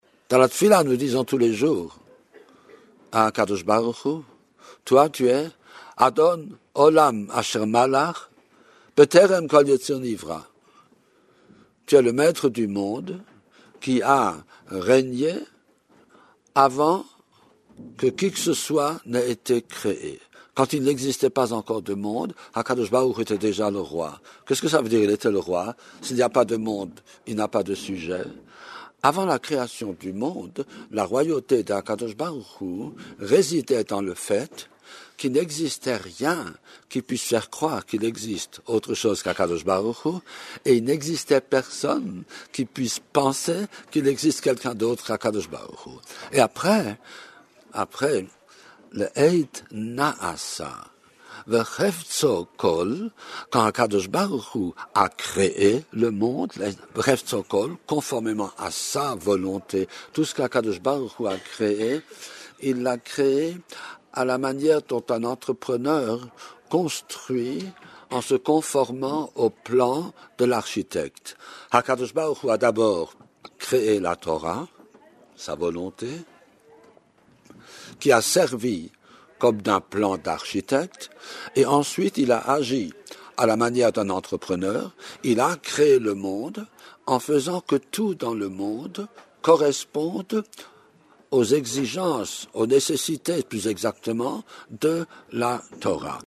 01:01:08 Ce cours a été donné le Rosh ‘Hodesh Adar 5769 – 24 février 2009, avec, comme point de départ, l’entrée dans le mois de Adar.